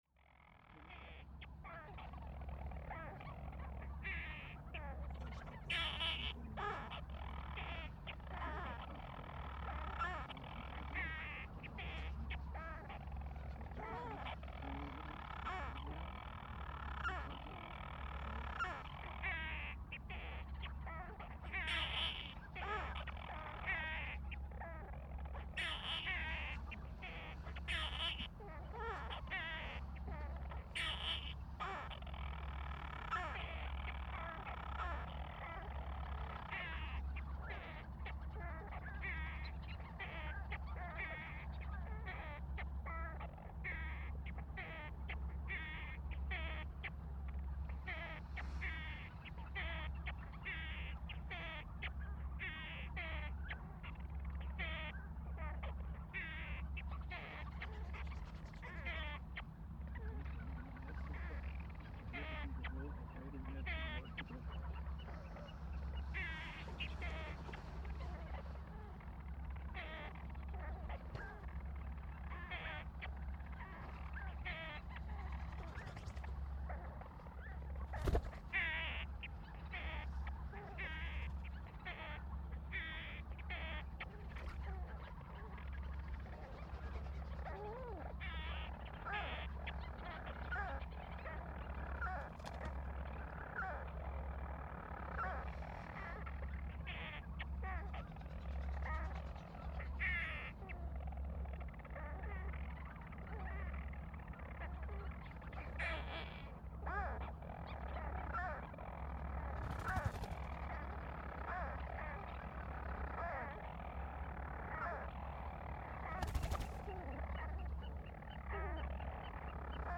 Elliðaey Island part 2
Storm petrel (Hydrobates pelagicus) is a seabird in the northern storm petrel family, Hydrobatidae.